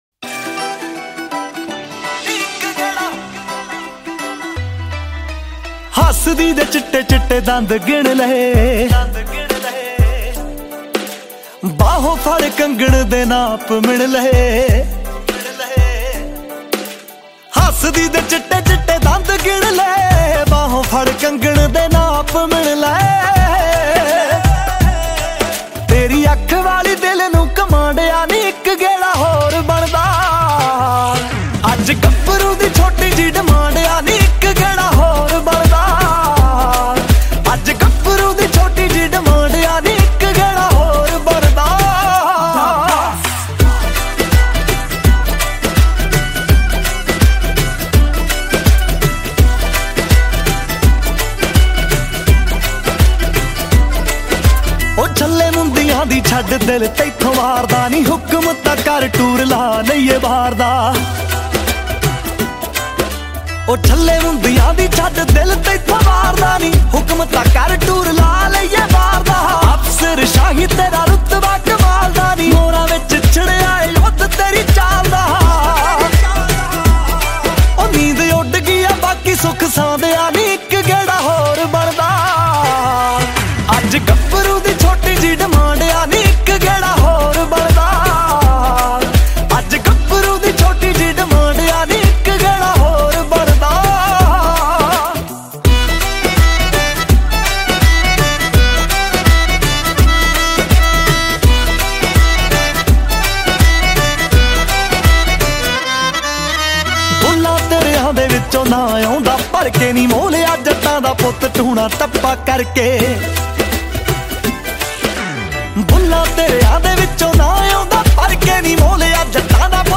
Punjabi Song